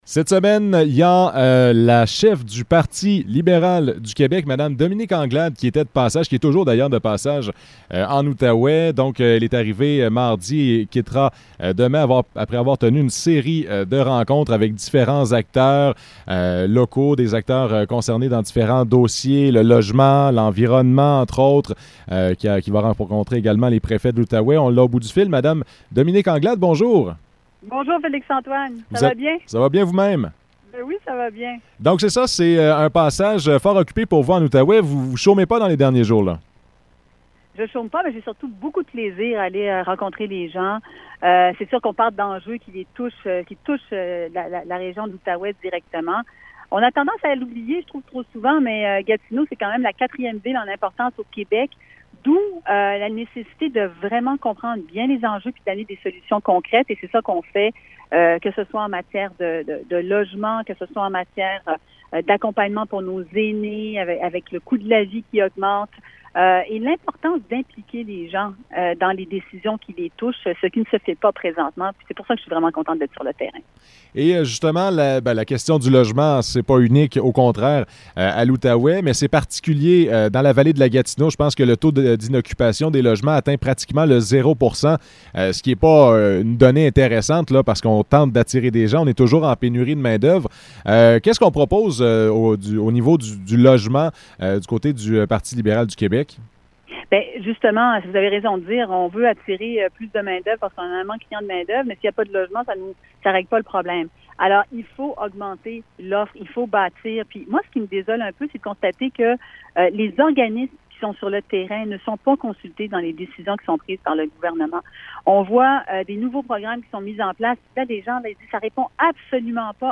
Entrevue avec Dominique Anglade, cheffe du parti libéral du Québec
entrevue-avec-dominique-anglade-cheffe-du-parti-liberal-du-quebec.mp3